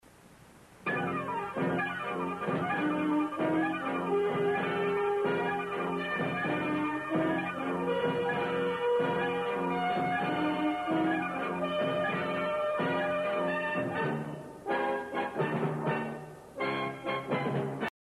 melody.